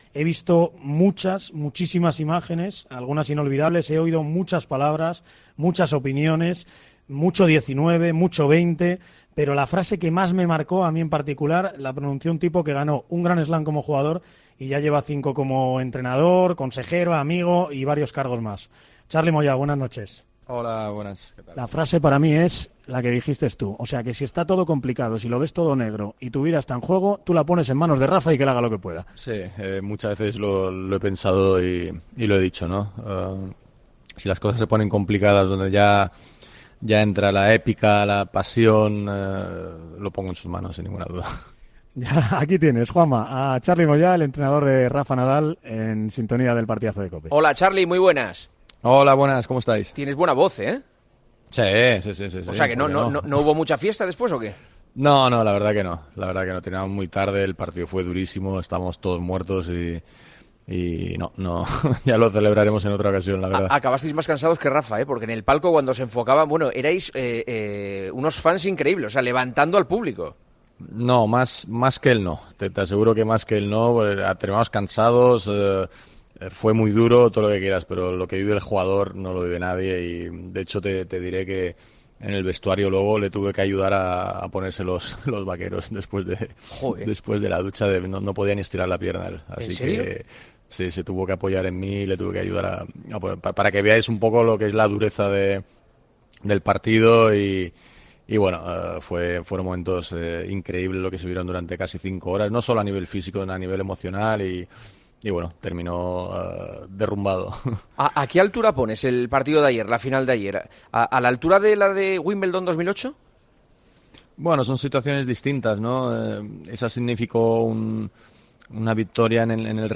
AUDIO: El entrenador del manacorí ha analizado en los micrófonos de El Partidazo de COPE la victoria de anoche en el US Open.
Entrevistas